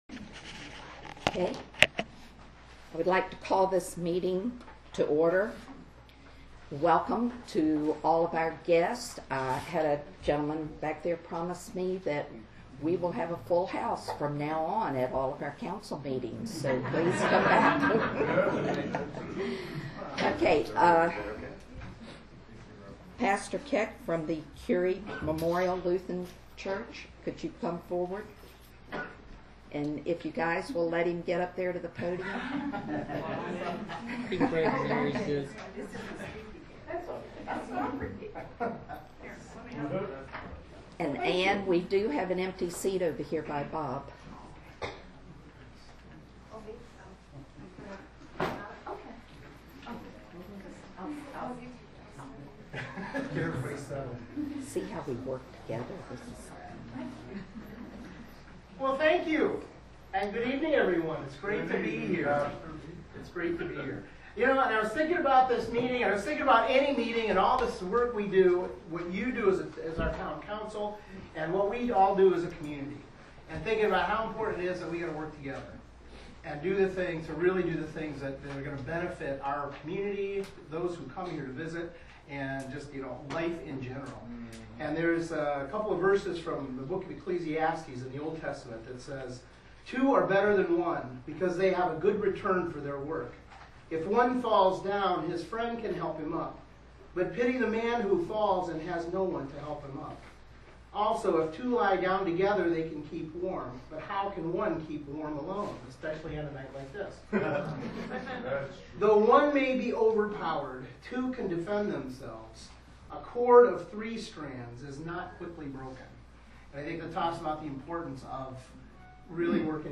Town Council Meeting